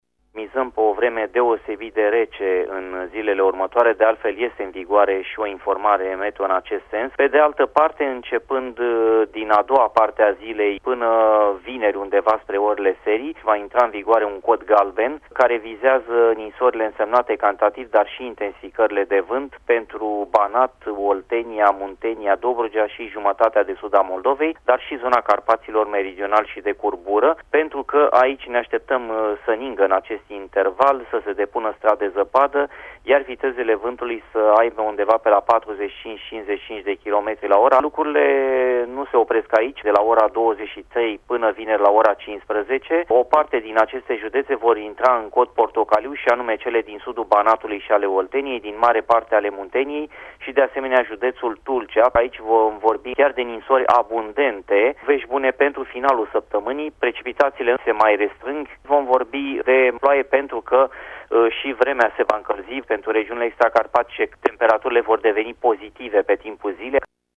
Diseară, de la ora 23, vremea va deveni şi mai rea în mai multe judeţe, care vor intra sub cod portocaliu. Meteorologul